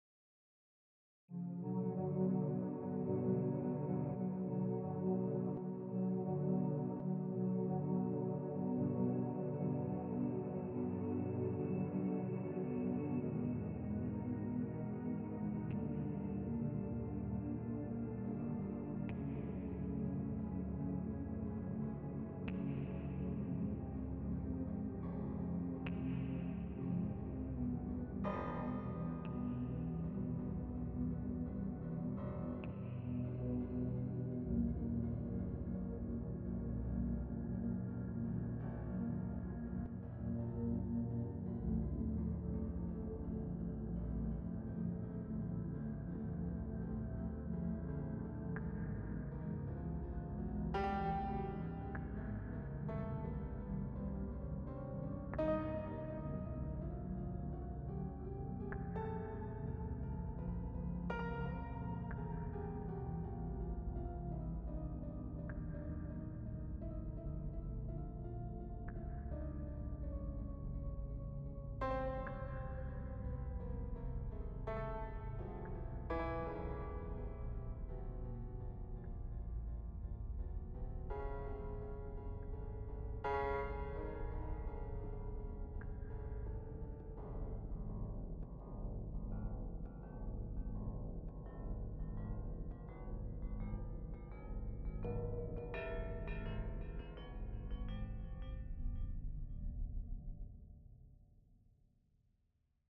A mix of three GPS recordings randomly put together. The volume is rather low so you might want to turn up the volume on your device a bit.